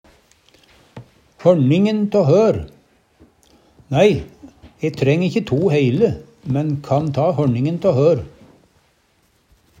DIALEKTORD PÅ NORMERT NORSK hørningen tå hør halvparten av kvar Eksempel på bruk Nei, e treng ikkje to heile, men kan ta hørningen tå hør. Hør på dette ordet Ordklasse: Uttrykk Kategori: Tal, mål, vekt Attende til søk